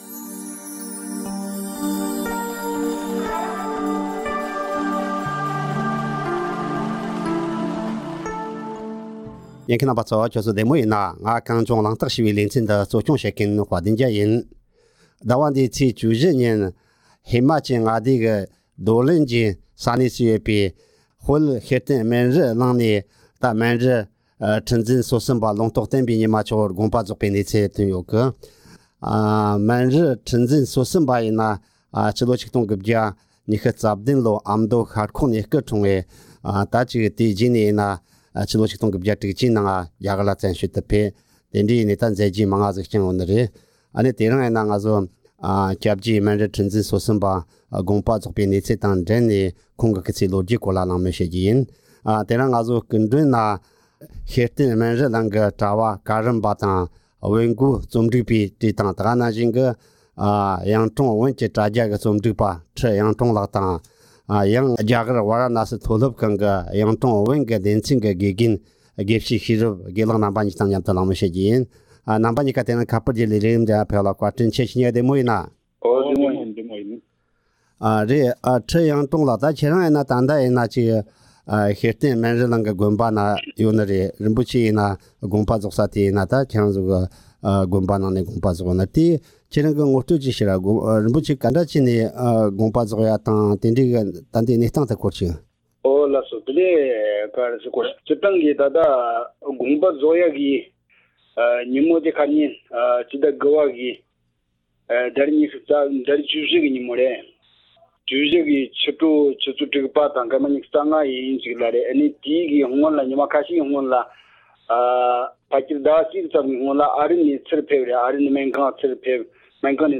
གླེང་མོལ་ཞུས་པ་གསན་རོགས་གནང་།